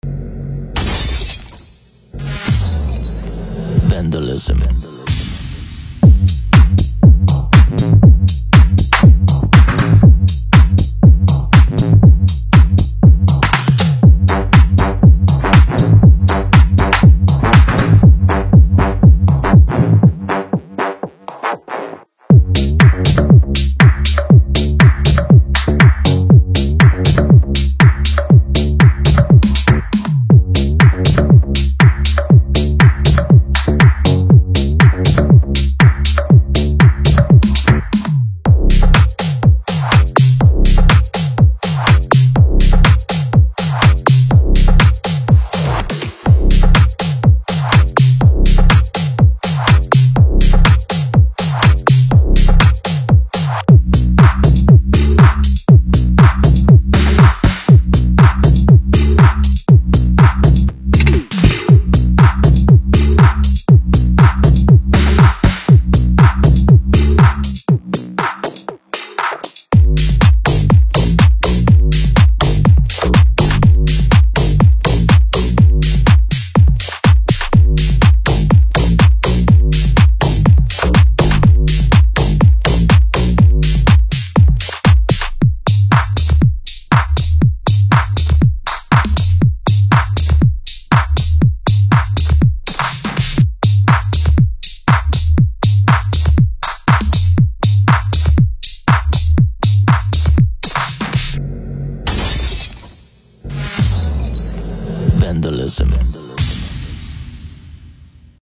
该系列为您带来精心设计、有力且独特的鼓。
支持试听： 是 试听格式/比特率： AAC 40kbps Play Pause Demo1 选择曲目,缓冲完成点play播放 注： 试听素材音质经过压缩处理,原素材未作任何修改。